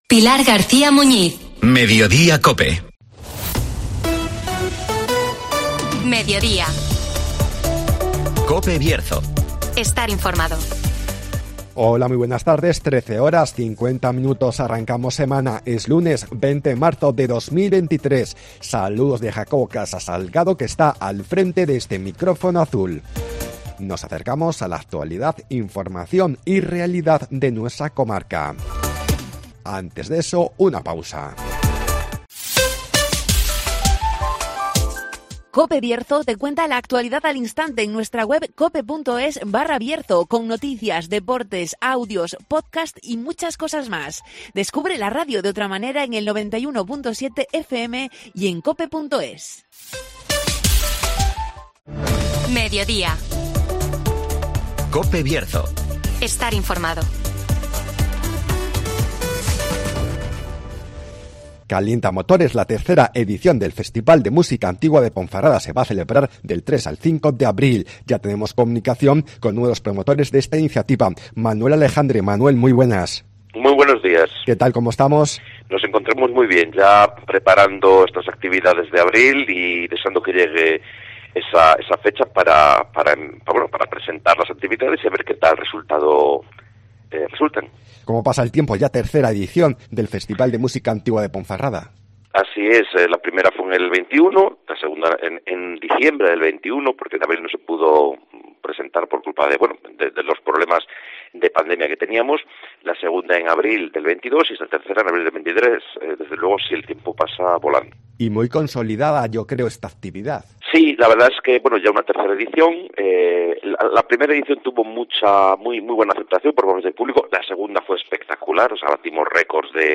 Calienta motores la tercera edición del Festival de Música Antigua de Ponferrada (Entrevista